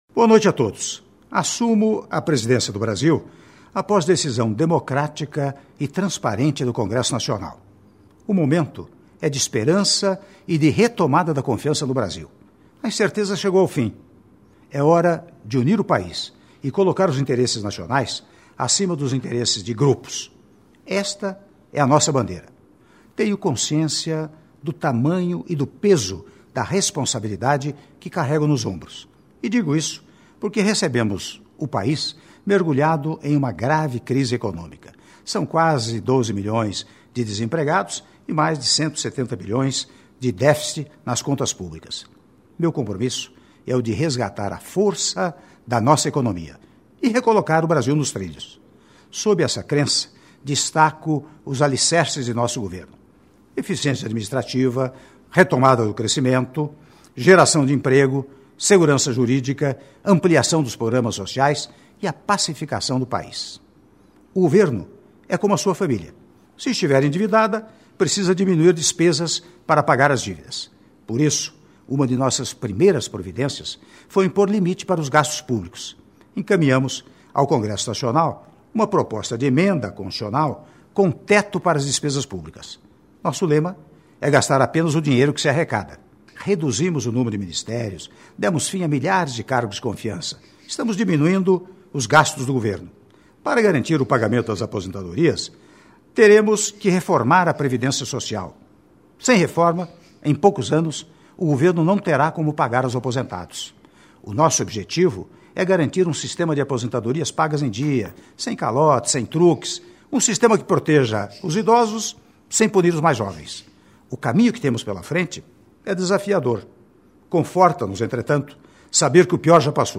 Áudio do pronunciamento do Senhor do Presidente da República, Michel Temer, em cadeia de rádio e televisão (04min45s)